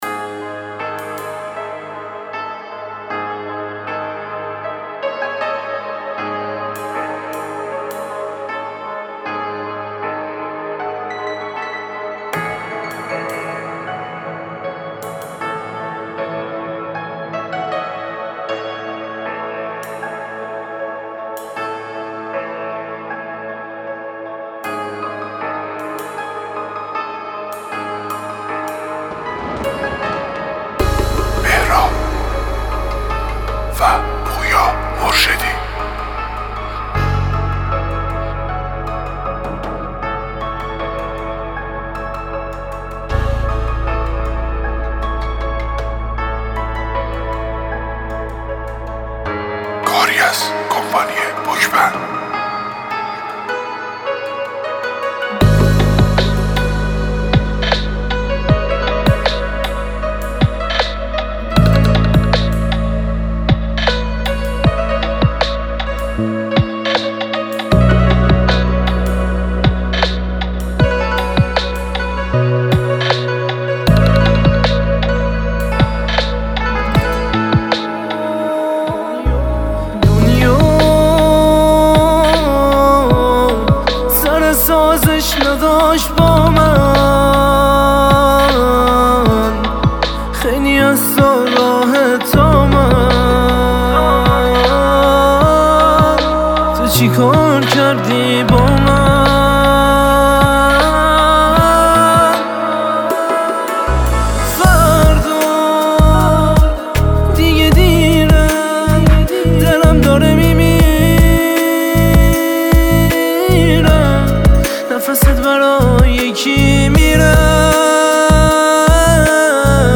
دانلود آهنگ دیس لاو